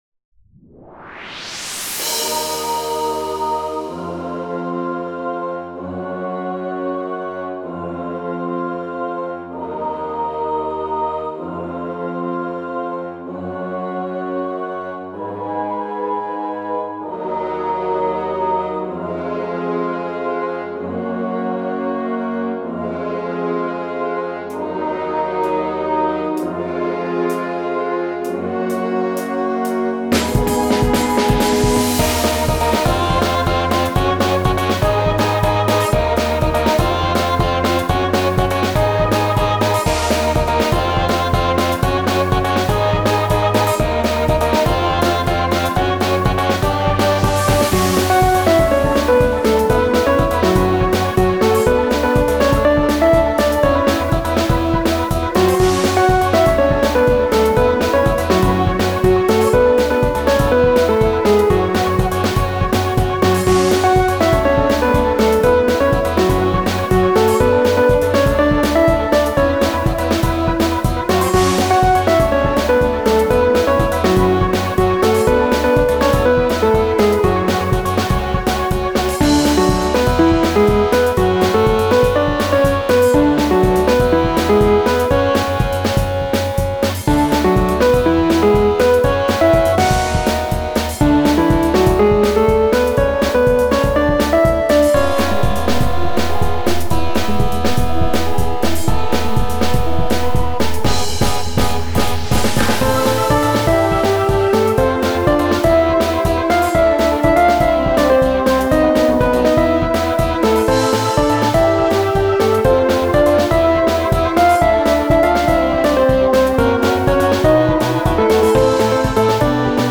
ところで曲名からは全く想像できないけど、これボス曲です。